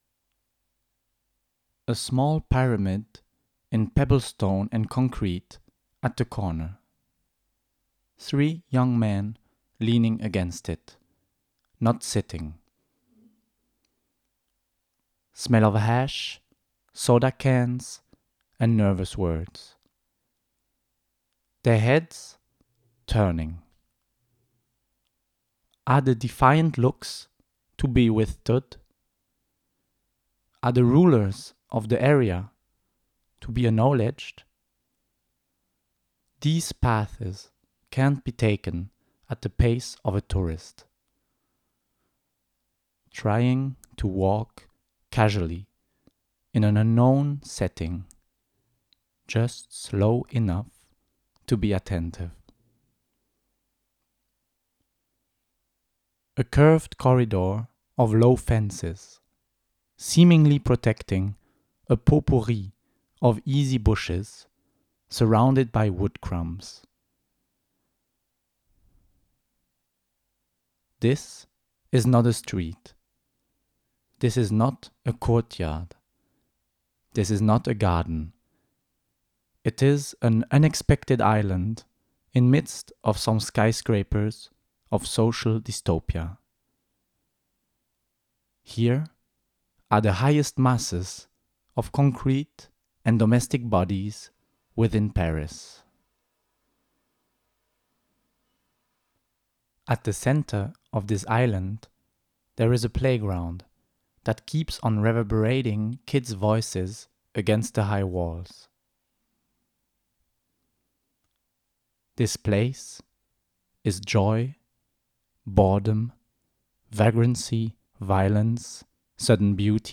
Two-channel audio piece, 4'15", 2015 Language: English